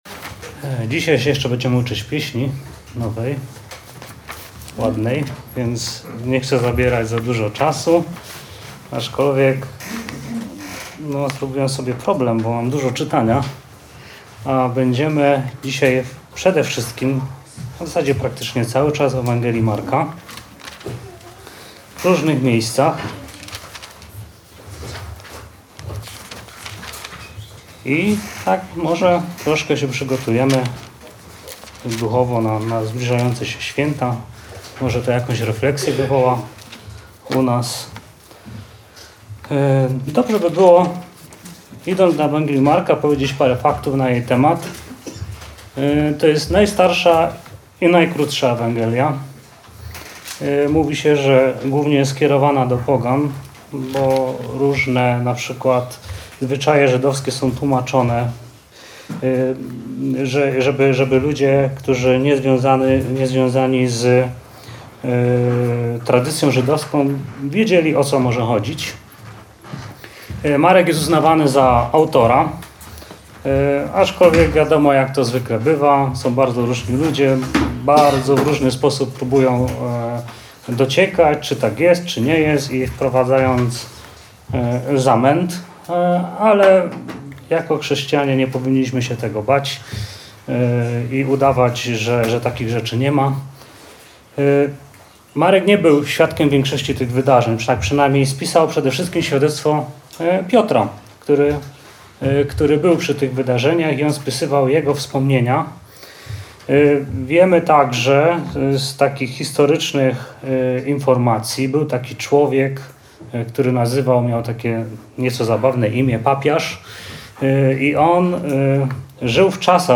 Podobał Ci się ten wykład?